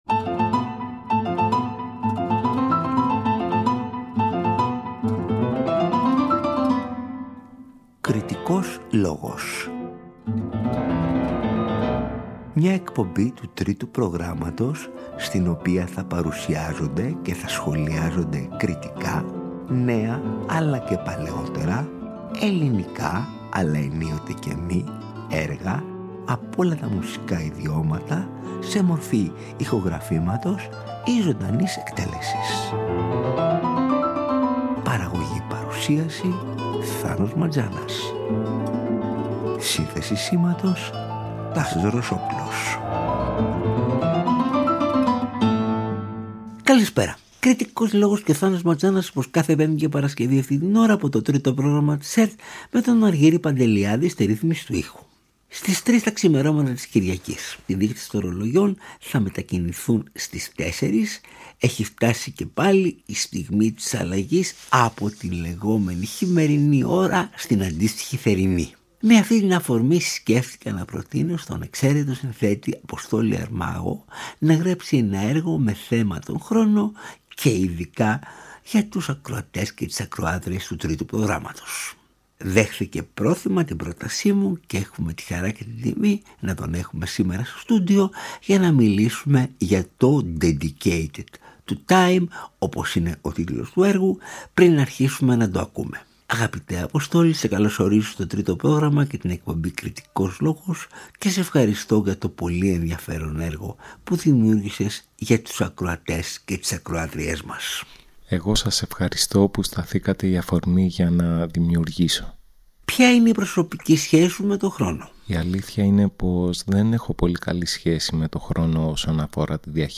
Διανθίζεται από δραματοποιημένες απαγγελίες της υψιφώνου